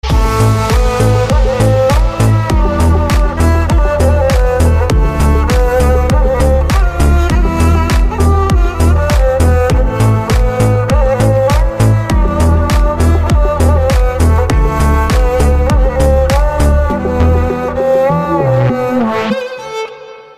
Рингтоны без слов
скрипка , восточные , Deep House